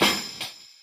Snare 7.wav